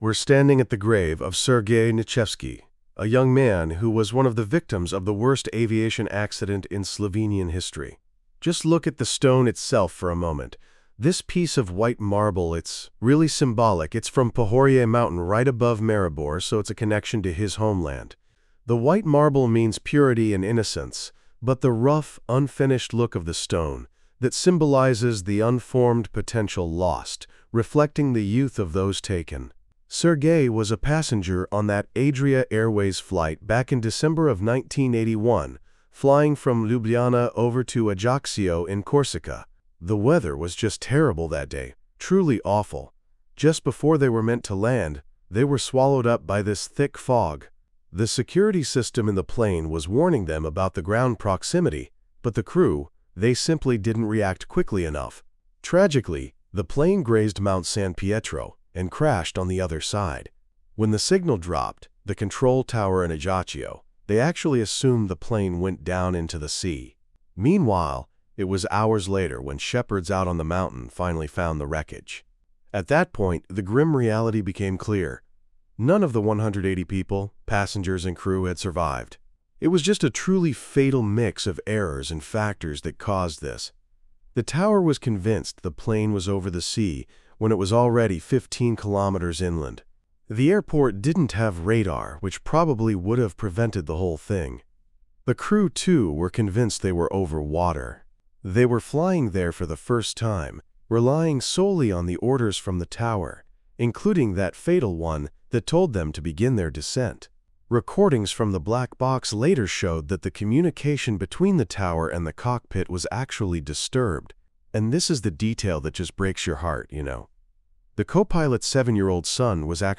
• Audio guide